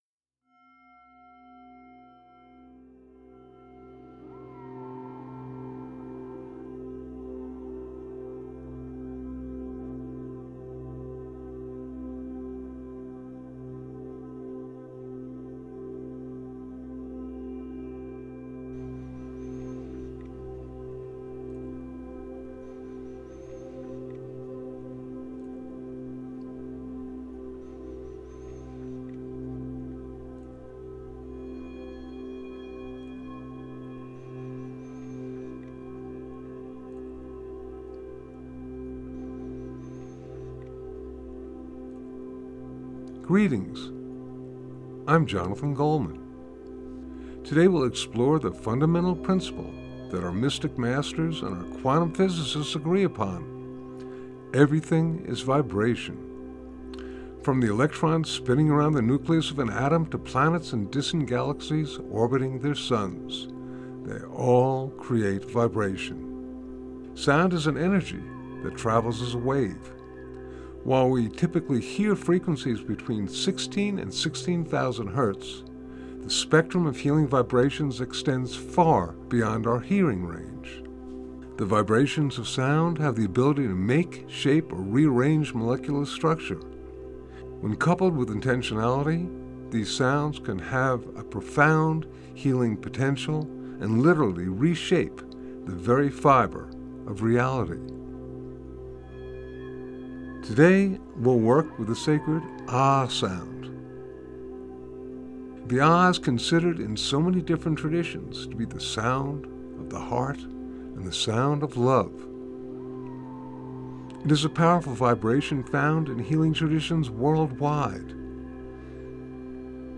With these concepts in mind, we work with the sacred AH sound in this session.